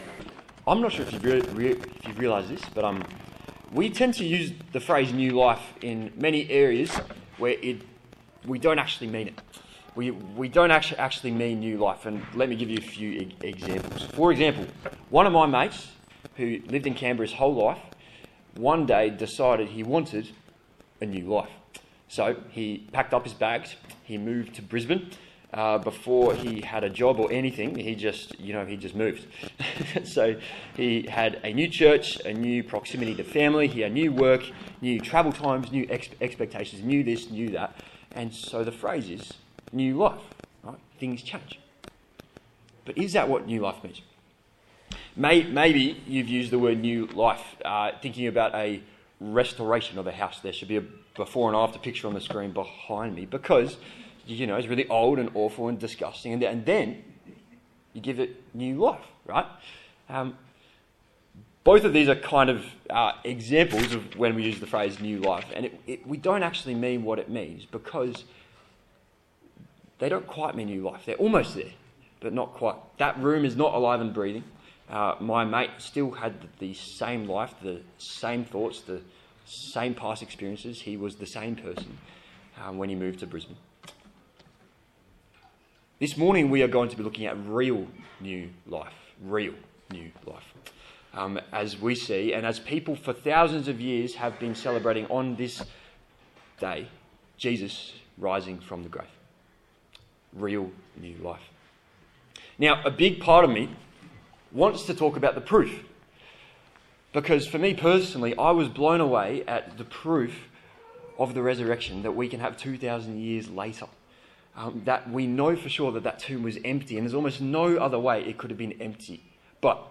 Easter Sunday 2026 Passage: Romans 6:1-14 Service Type: Easter Sunday A sermon on the letter to the Romans on Easter